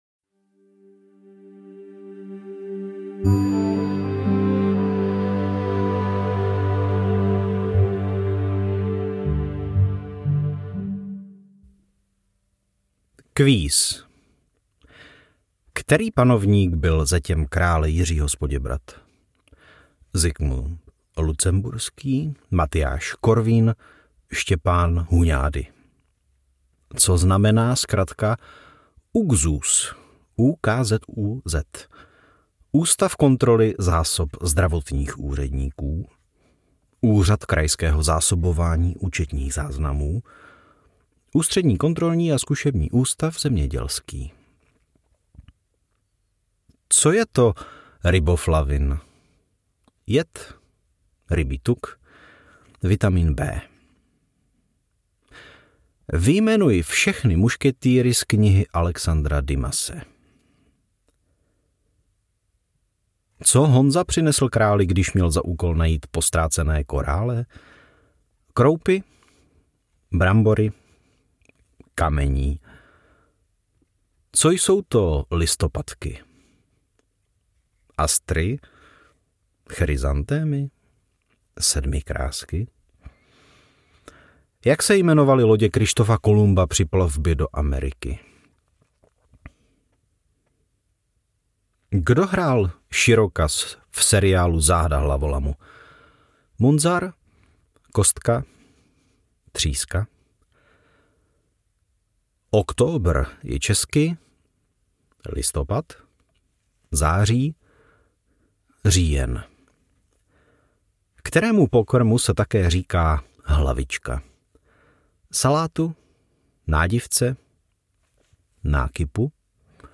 SONS ČR - PAPRSEK ŘÍJEN 2022 NAČTENÝ